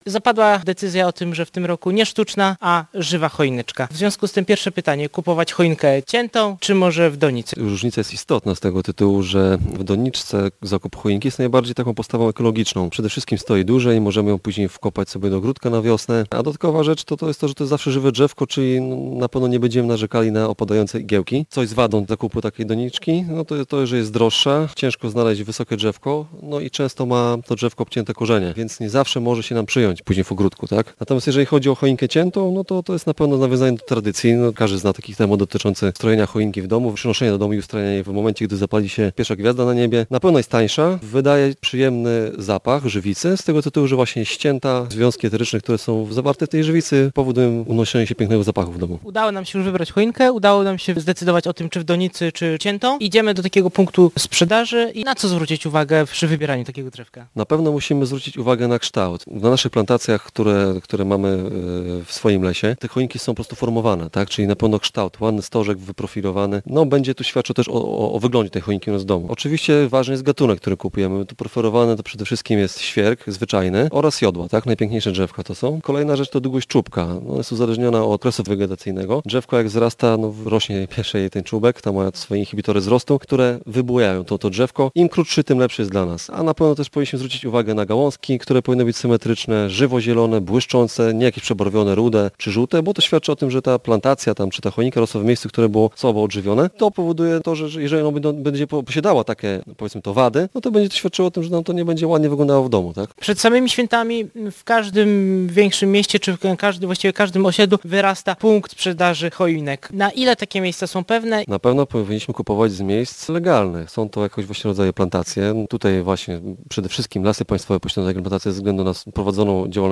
Ważne są kształt i barwa. Leśnik radzi, jak wybrać świąteczne drzewko [ROZMOWA]